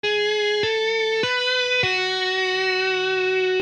Pahoittelen kammottavaa tietokonesoundia.
Riffi 3
Riffi lähtee duurissa, vaikka muuta saattaisi odottaa.